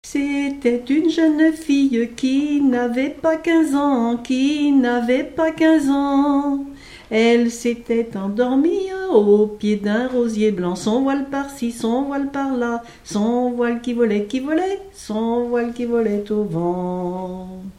Genre laisse
Enquête Arexcpo en Vendée-C.C. Yonnais
Pièce musicale inédite